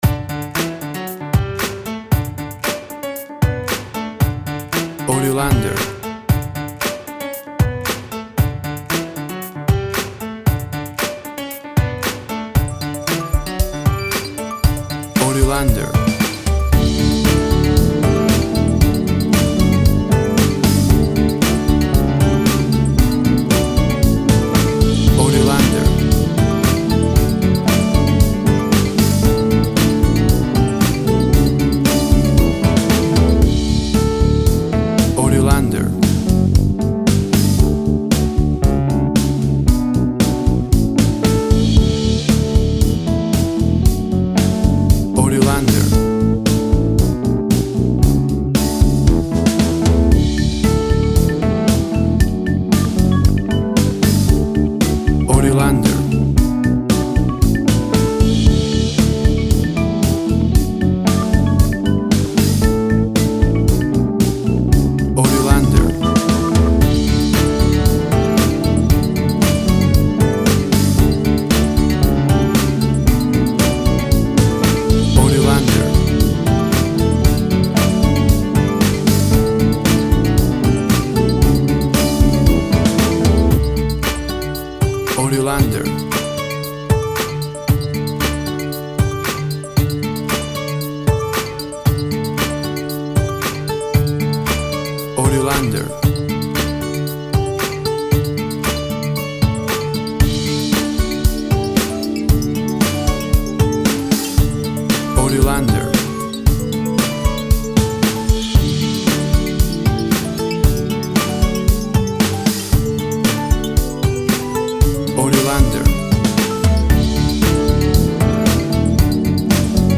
WAV Sample Rate 16-Bit Stereo, 44.1 kHz
Tempo (BPM) 115